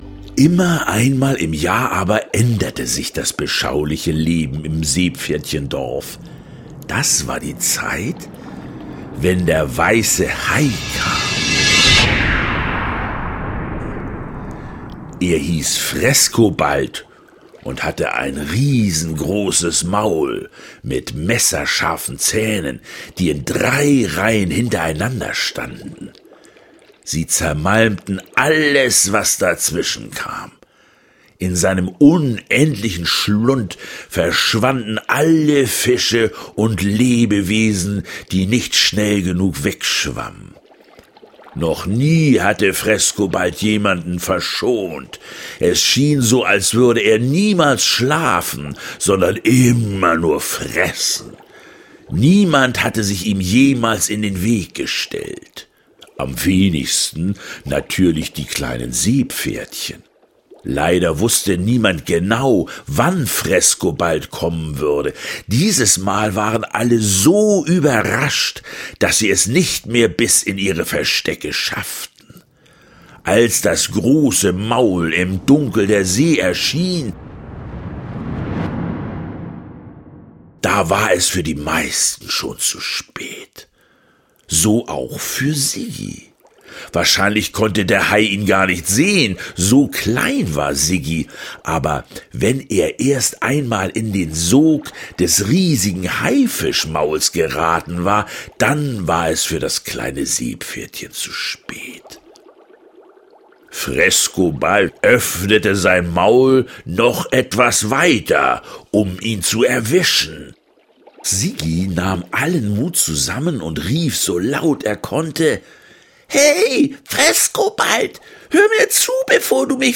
Hörbuch-CD oder als Download, mit Liedern zum Mitsingen und Tanzen, 6,90 €
Siggi und Frescobald – Hörbuch